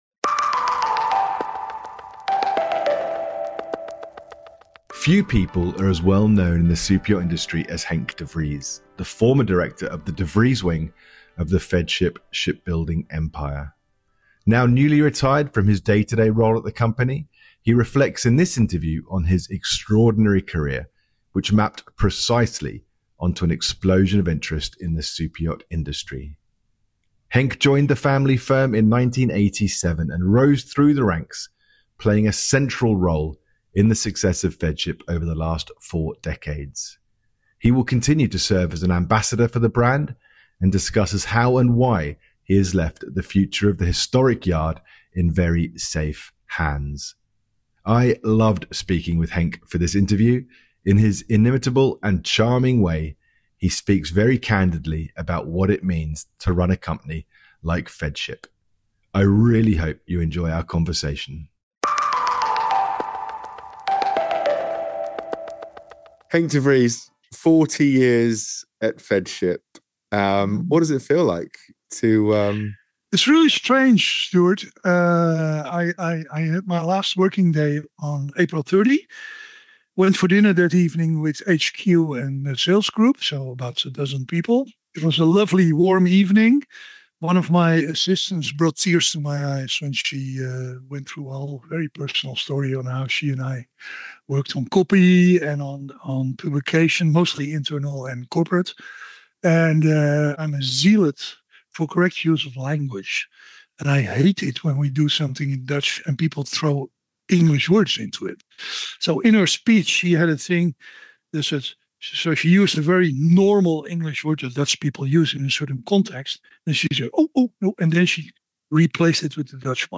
In this interview, he charts his rise to the top of the world of superyachting, and how and why he has decided now to take on a more supporting role at the company.